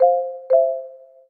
Звуки уведомлений Samsung